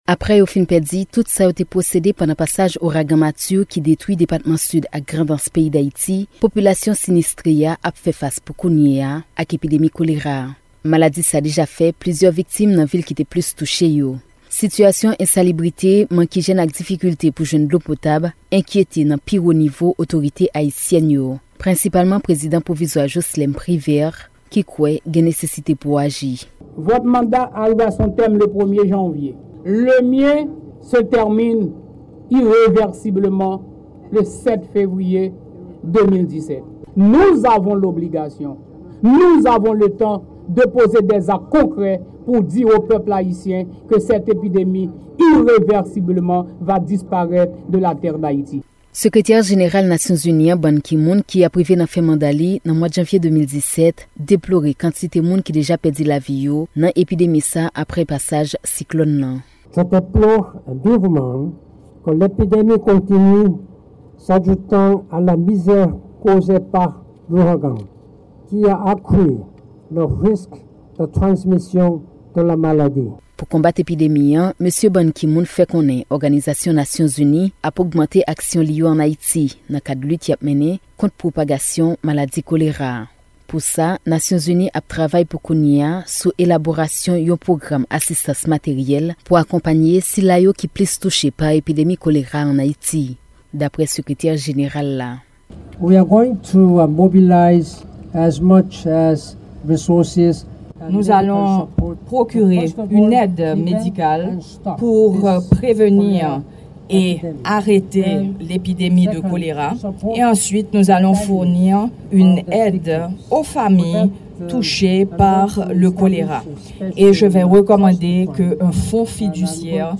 repòtaj depi Pòtoprens